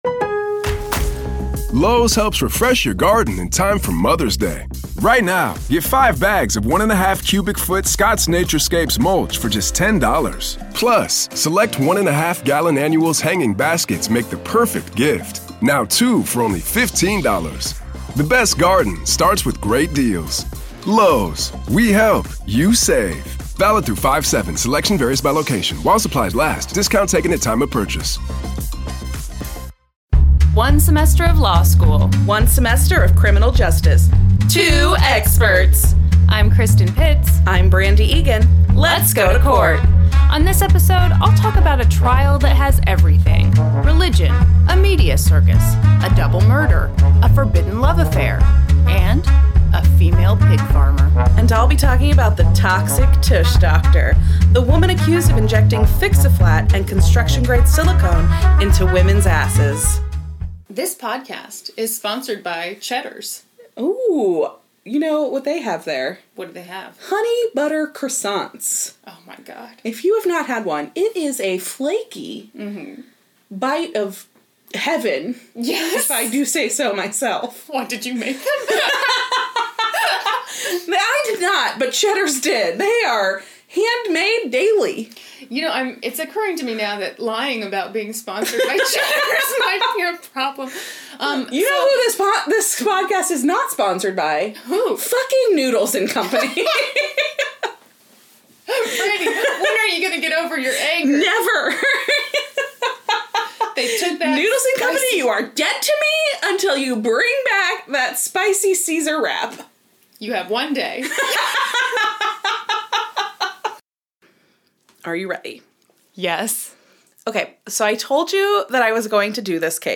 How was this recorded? WARNING: The audio in this episode is rough. We were young(ish), dumb, and thought we’d save a little money by sharing one microphone.